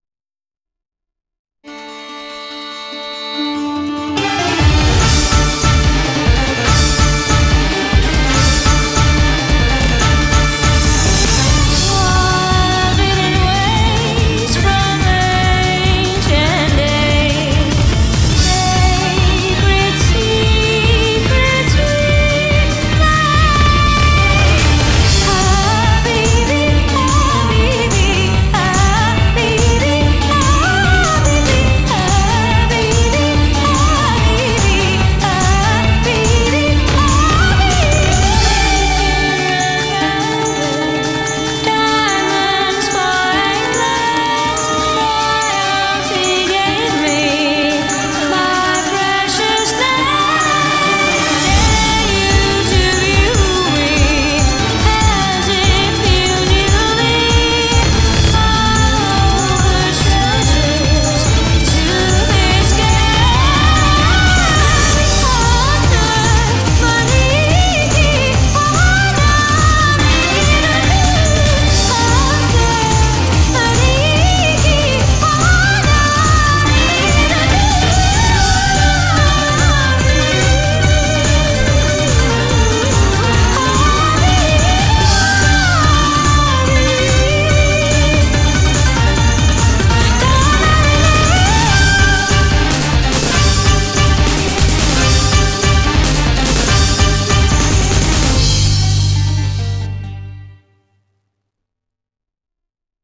BPM144
Audio QualityLine Out